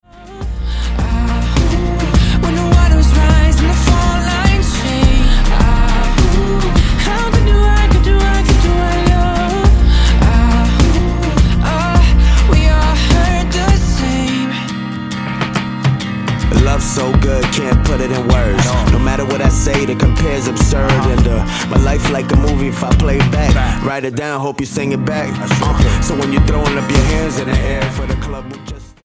Style: Hip-Hop